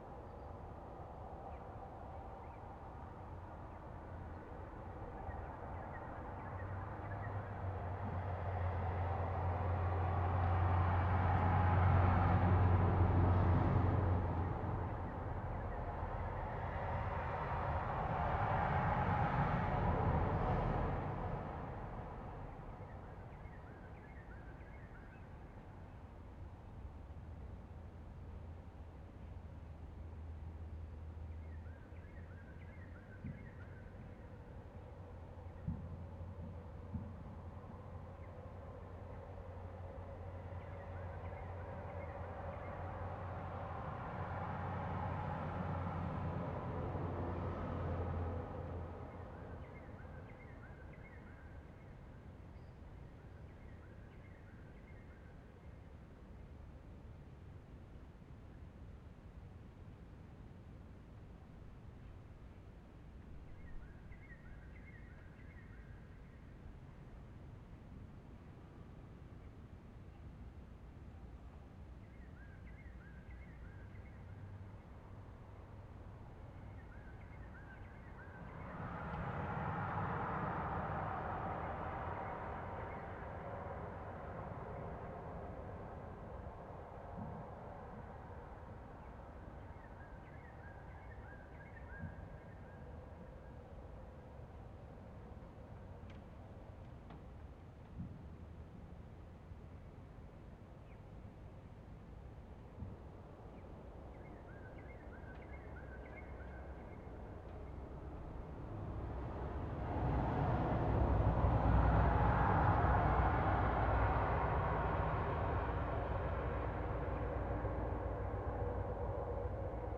crickets.ogg